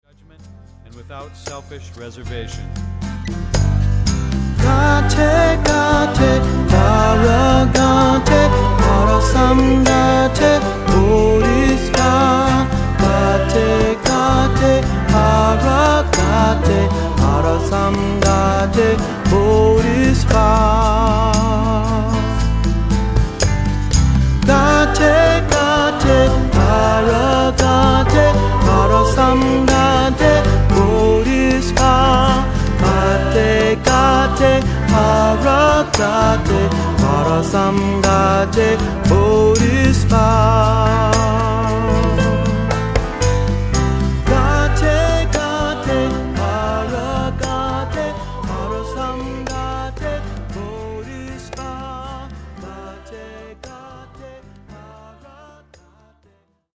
Accompaniment:      Keyboard
Music Category:      Christian
Pronounce that "gah-te."